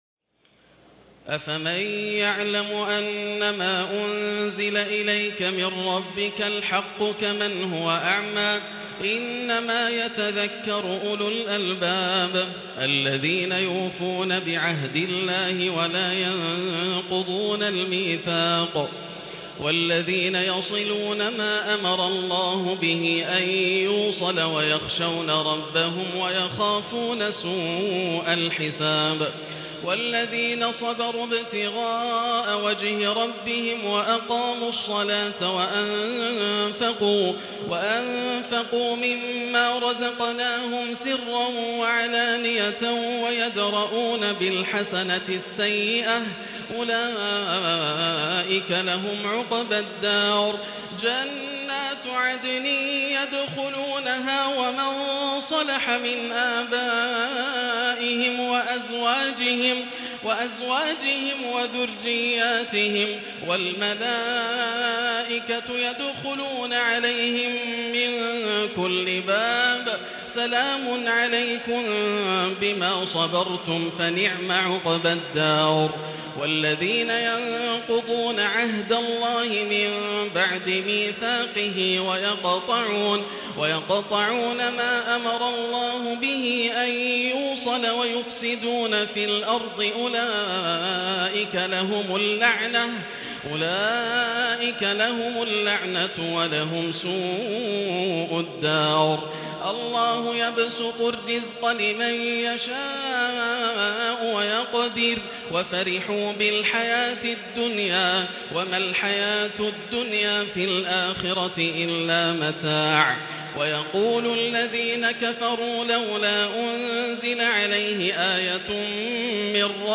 تراويح الليلة الثانية عشر رمضان 1440هـ من سورتي الرعد (19-43) و إبراهيم كاملة Taraweeh 12 st night Ramadan 1440H from Surah Ar-Ra'd and Ibrahim > تراويح الحرم المكي عام 1440 🕋 > التراويح - تلاوات الحرمين